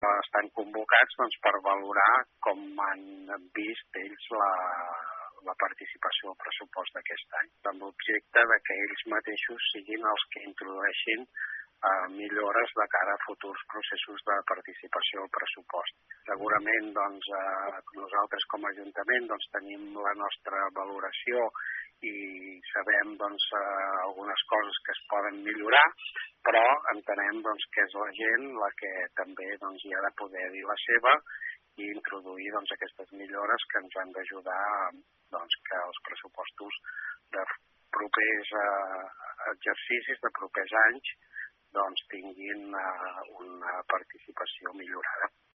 Ho explica Ramir Roger, regidor de Participació Ciutadana a l’Ajuntament de Malgrat de Mar.